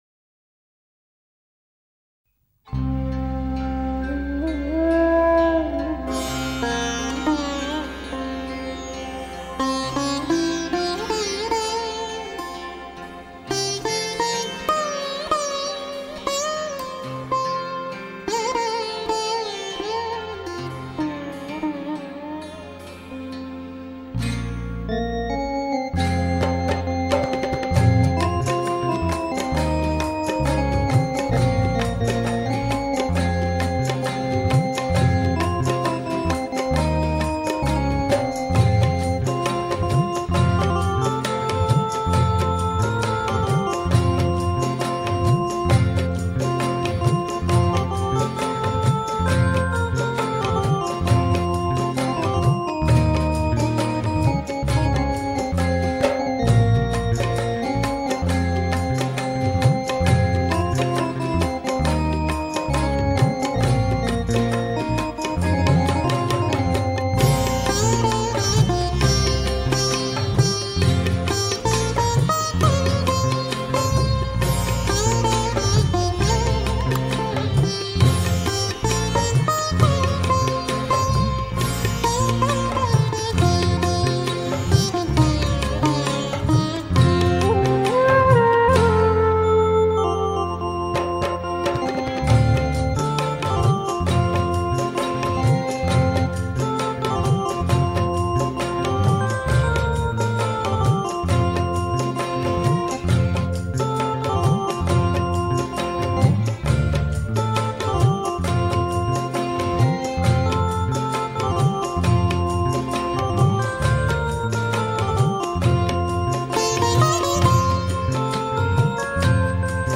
Instrumental - 12